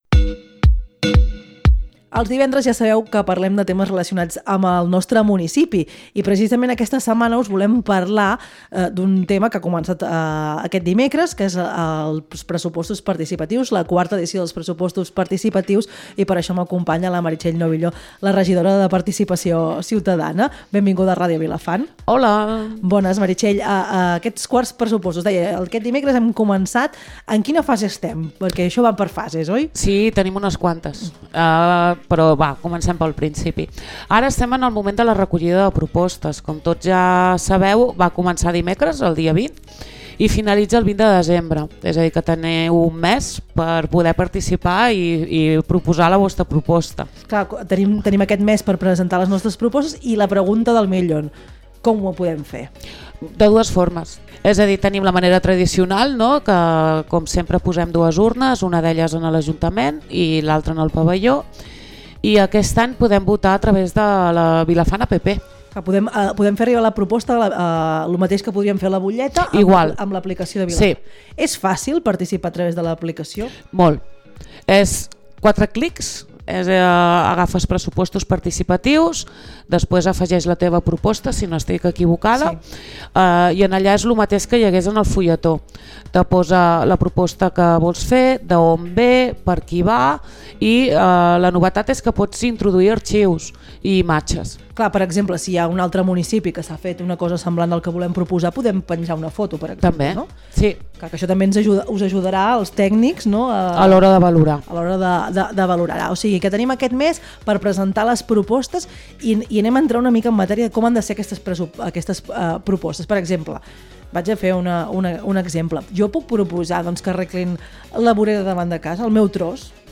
Hem parlat amb la regidora de participació ciutadana, Meritxell Novillo, sobre la 1a fase dels 4ts pressupostos participatius que han arrencat aquest dimecres. Durant l’entrevista ens ha explicat com han de ser les propostes que tots i totes podem fer arribar per millorar Vilafant en aquest procés participatiu.